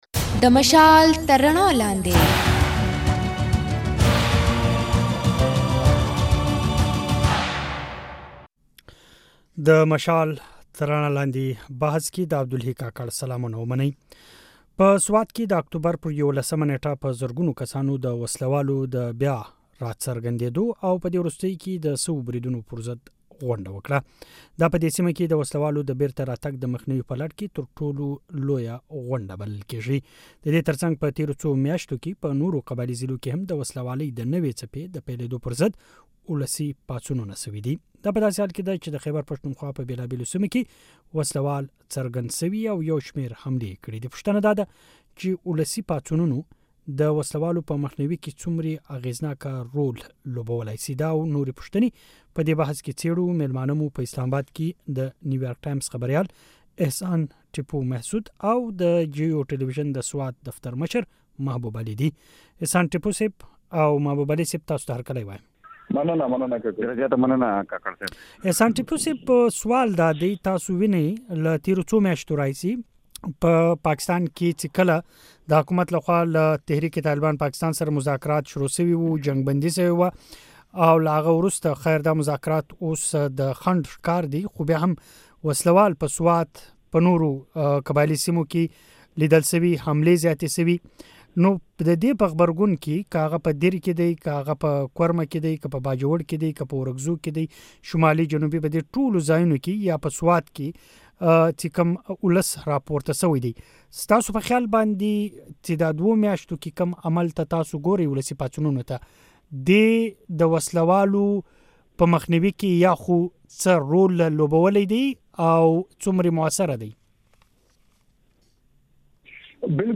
پر دې موضوع بحث د غږ په ځای کې واورئ.